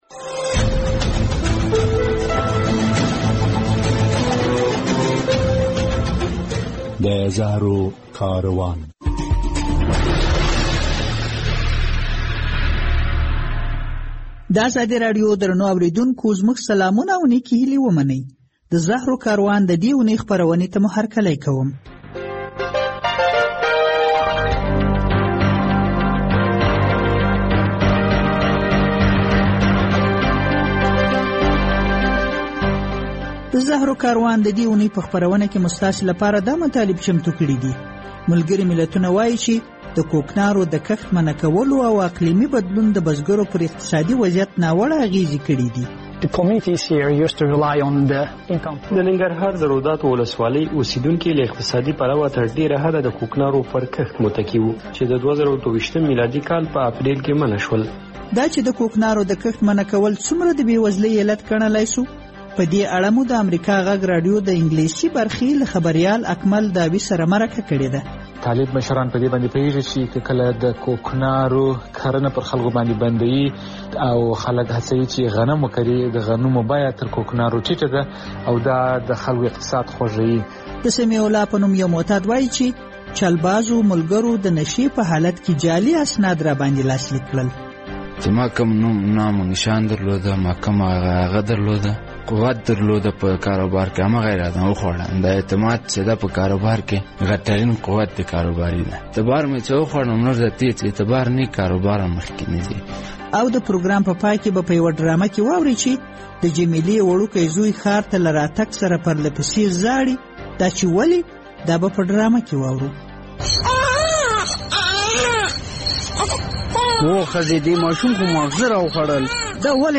د زهرو کاروان په دی خپرونه کې به واورئ چې ملګري ملتونه وایي د کوکنارو نه کرلو پرېکړی او اقلیمي بدلون بزګران له جدي اقتصادي ستونزو سره مخ کړي. په یوه مرکه کې مو دا مسله څېړلې چې ایا د طالبانو له لوري د کوکنارو نه کرلو پرېکړې دوام به نړۍ دې ته وهڅوي چې د دوی حکومت په رسمیت وپیژني؟